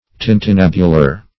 Tintinnabular \Tin`tin*nab"u*lar\